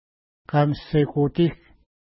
Pronunciation: ka:məssekuti:k
Pronunciation